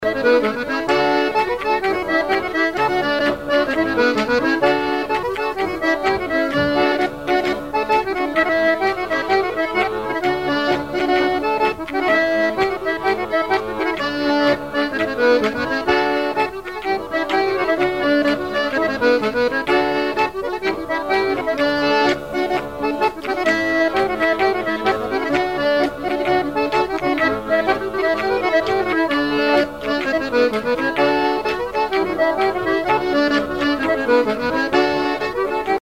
branle : courante, maraîchine
bal traditionnel à la Minoterie, à Luçon
Pièce musicale inédite